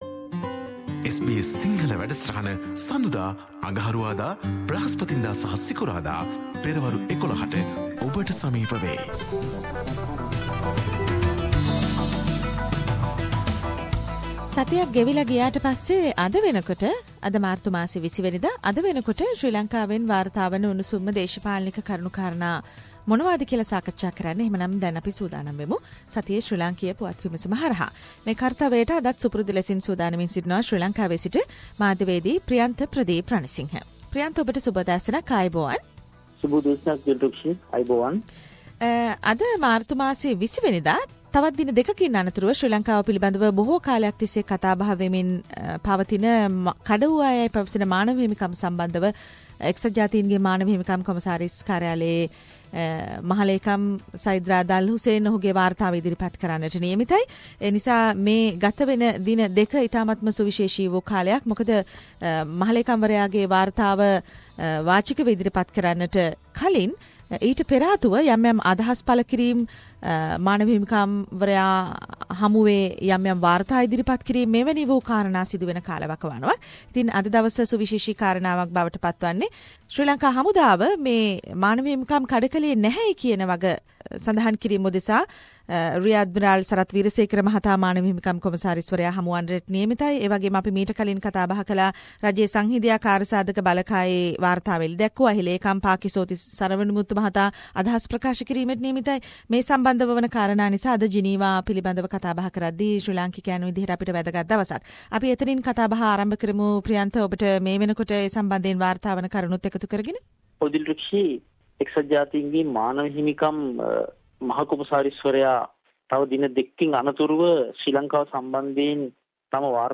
reports from Sri Lanka